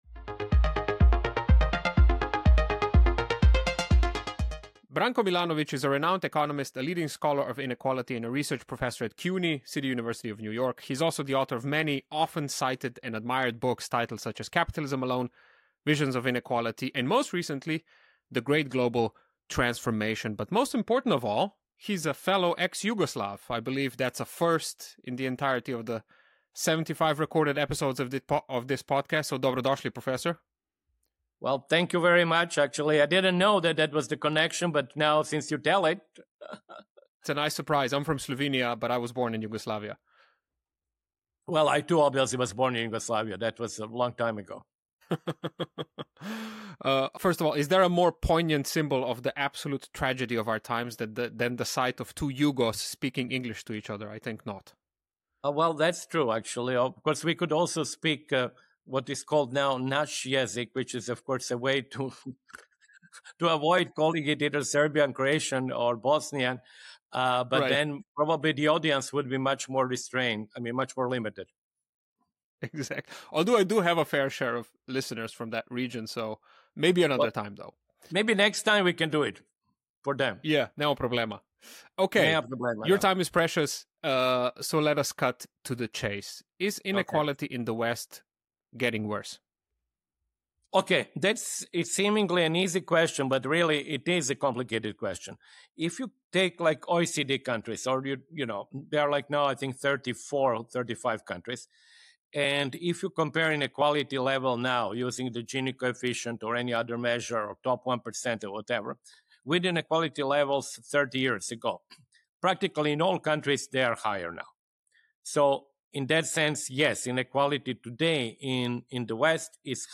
More in my conversation with Branko!